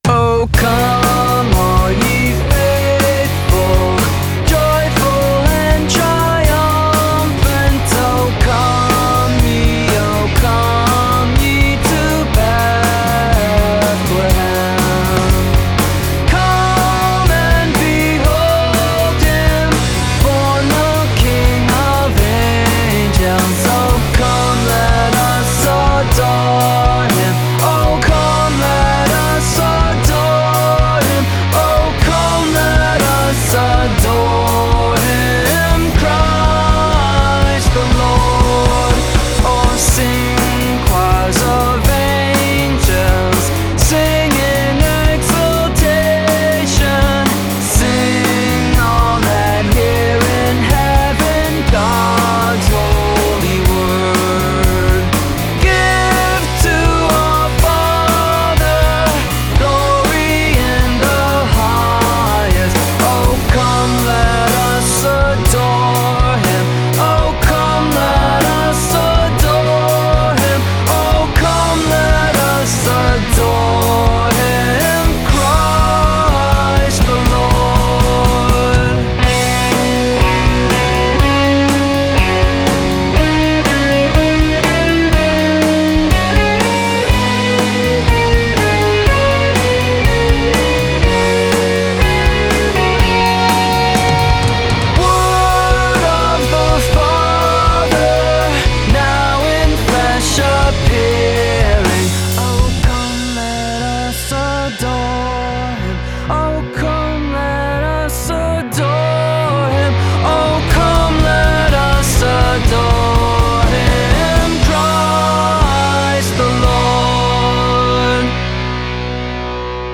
cheeky punk-pop makeovers of classic poprock songs